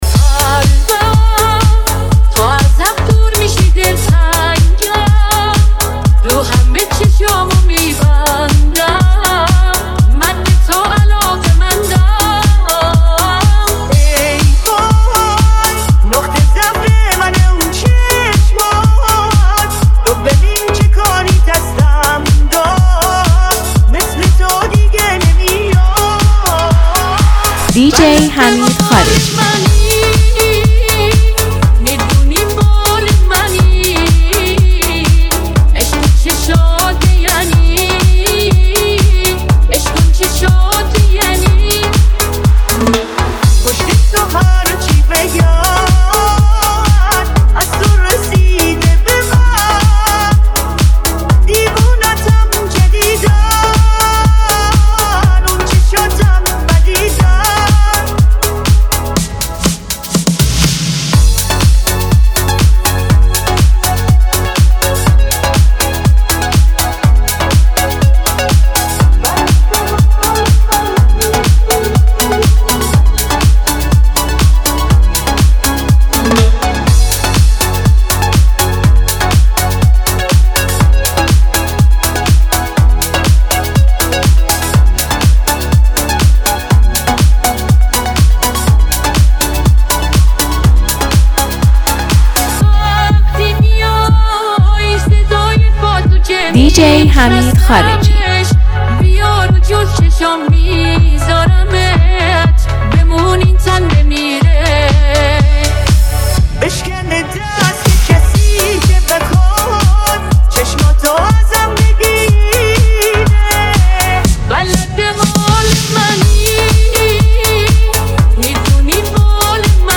با تکنولوژی هوش مصنوعی